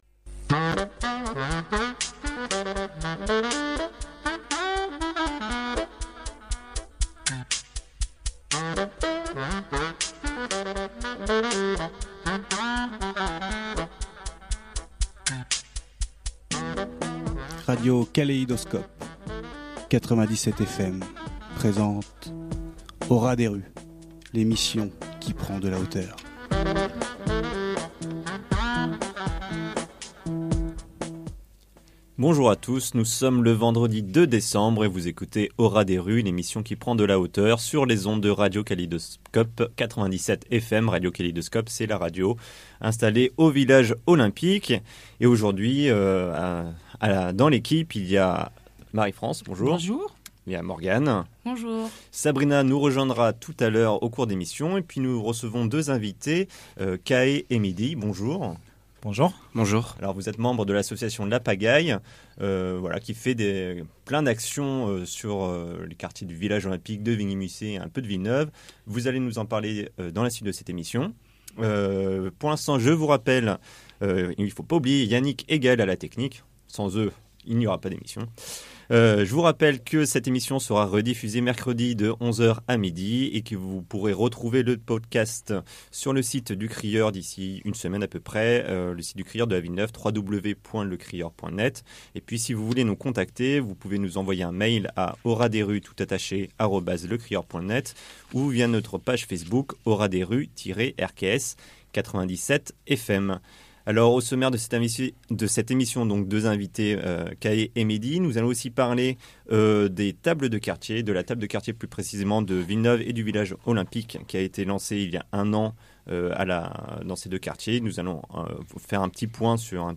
Au ras des rues est une émission hebdomadaire sur Radio Kaléidoscope (97 fm) qui s’intéresse à l’actualité des quartiers sud de Grenoble et de l’agglo : Villeneuve, Village Olympique, Mistral, Abbaye-Jouhaux, Ville Neuve d’Échirolles…